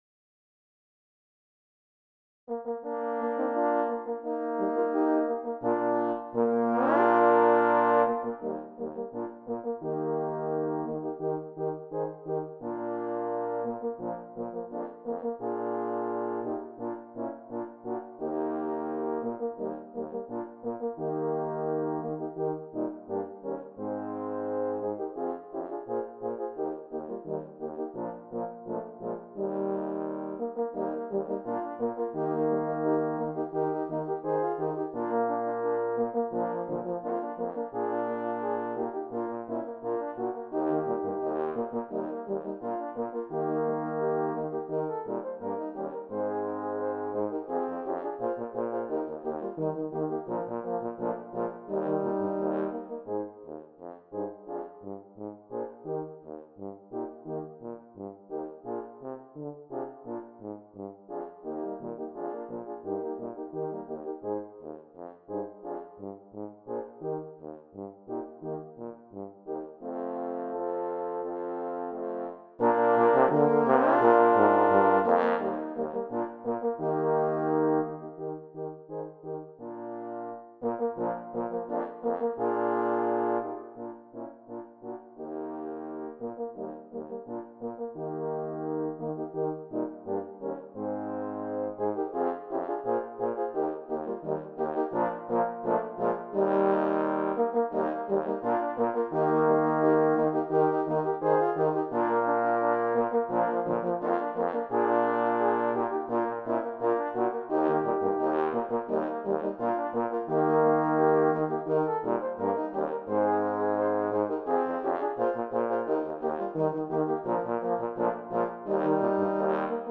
Here is a little HORN QUARTET I created in about three hours on Sunday morning , 3/1/20.
I used the Finale software for note input and audio output.
BRASS MUSIC